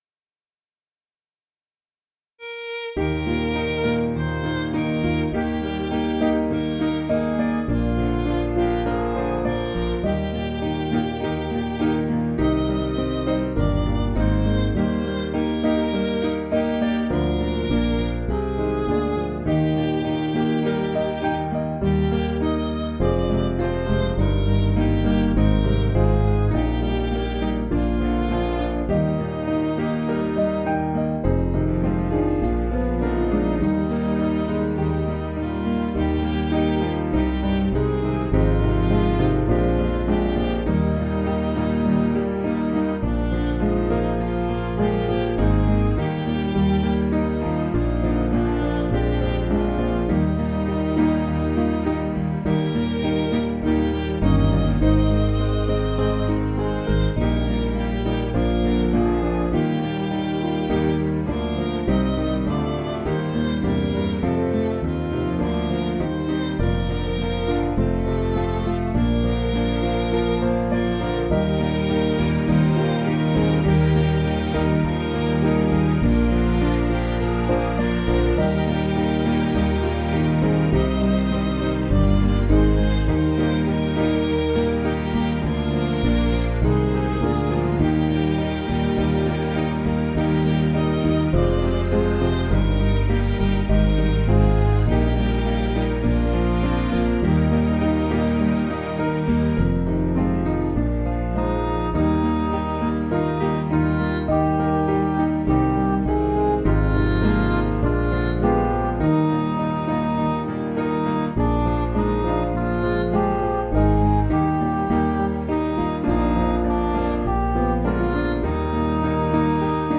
Piano & Instrumental
(CM)   3/Eb
Midi